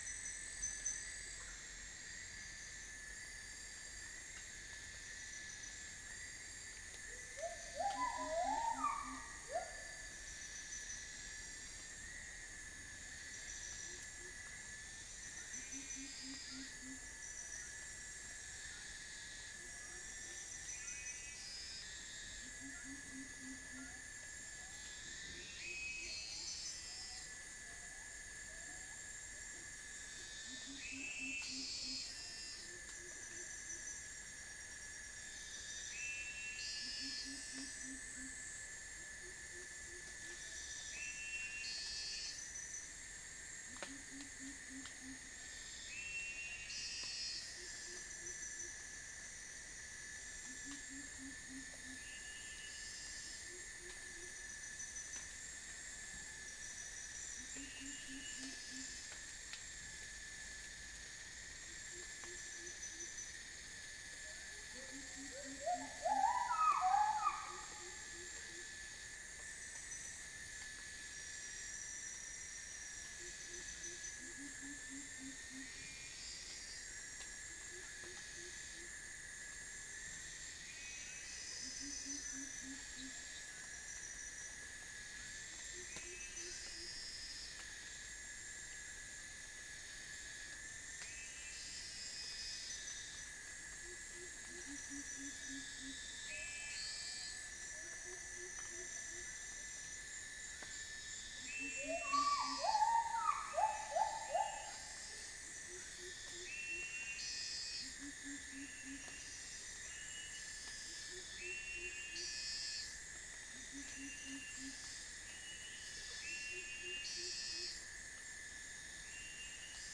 Centropus sinensis
PICIDAE
Mixornis gularis
Halcyon smyrnensis